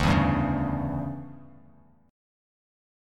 DM7sus2sus4 chord